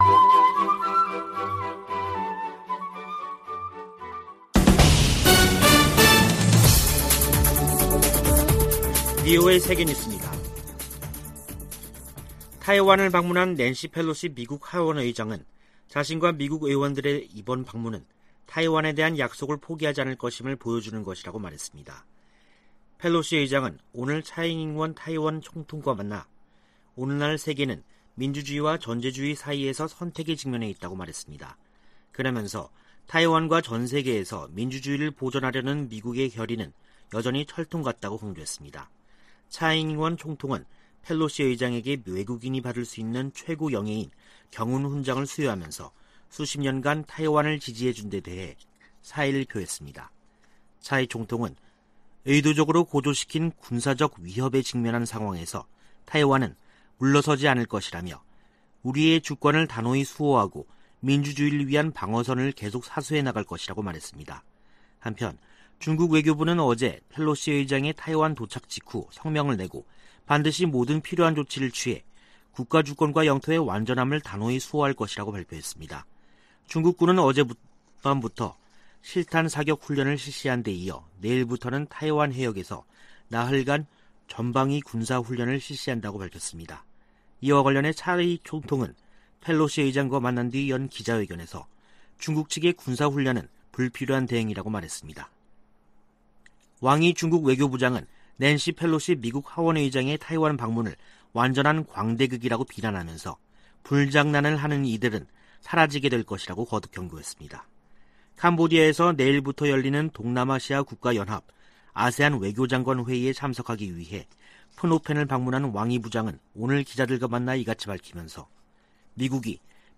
VOA 한국어 간판 뉴스 프로그램 '뉴스 투데이', 2022년 8월 3일 2부 방송입니다. 미 국무부는 ‘확인된 정보’가 없다는 중국 측 주장에 대해, 북한이 7차 핵실험을 준비하고 있는 것으로 평가한다고 밝혔습니다.